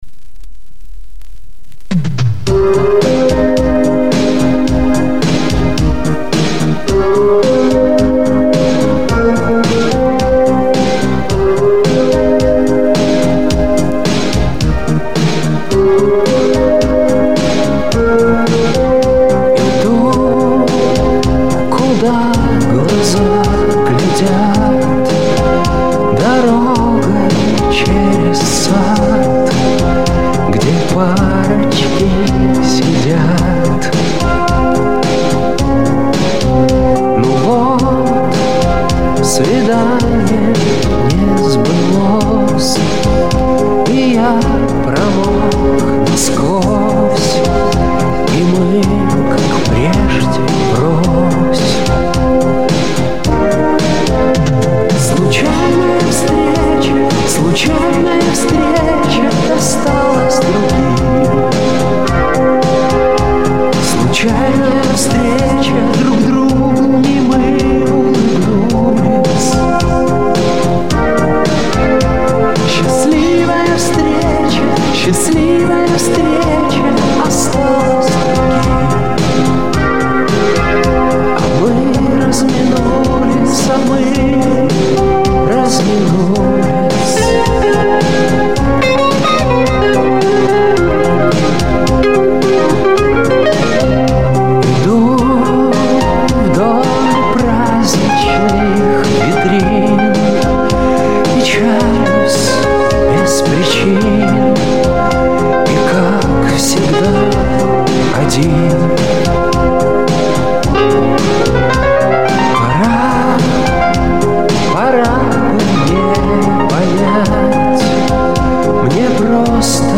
ОЦИФРОВКА С ПЛАСТИНКИ